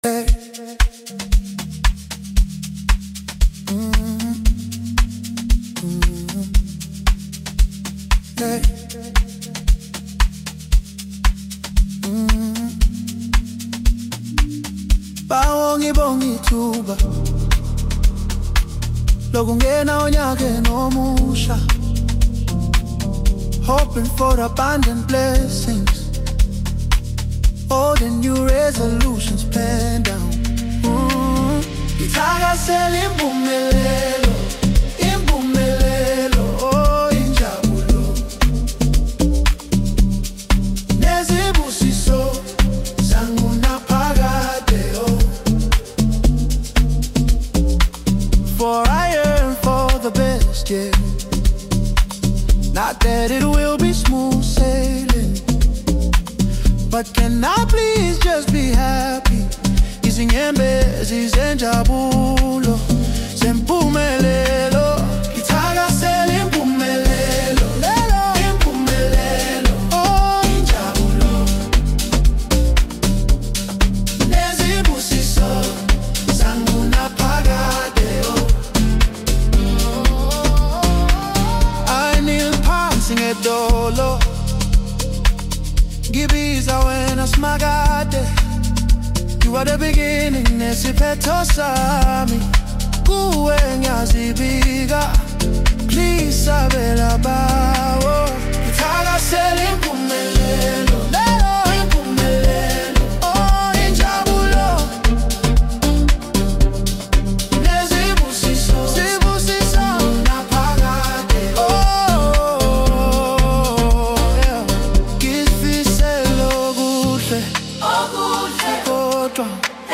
Home » Amapiano
South African singer